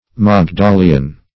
Search Result for " magdaleon" : The Collaborative International Dictionary of English v.0.48: Magdaleon \Mag*da"le*on\, n. [NL., fr. Gr.